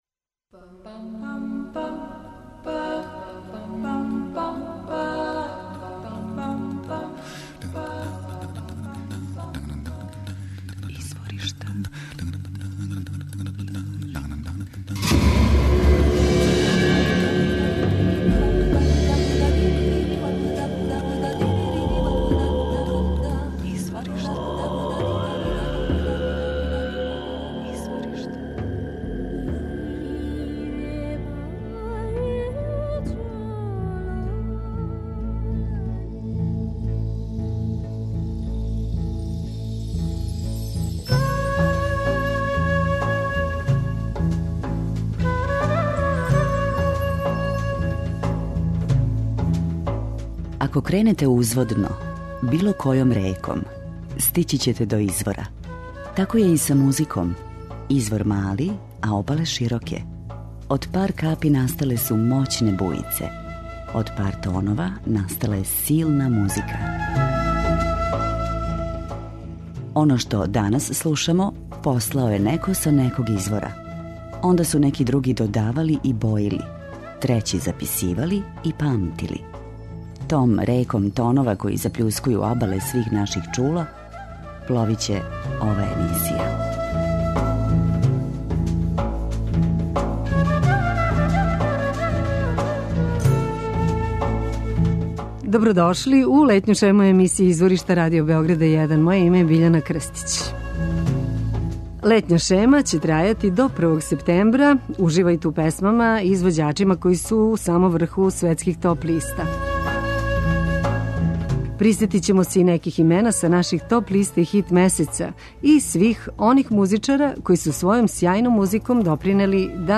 Током летње шеме, имате прилику да слушате избор из свих досадашњих емисија. Дружићемо се са музичарима чију музику дефинишу у оквирима world music.